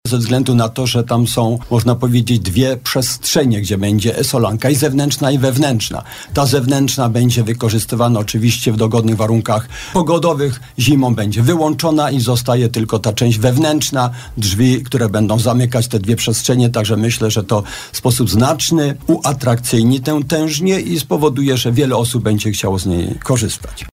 Powstaje obiekt wyjątkowy, ze względu na to, że tam są dwie przestrzenie, gdzie będzie solanka zewnętrzna i wewnętrzna – mówi burmistrz Czechowice-Dziedzic Marian Błachut.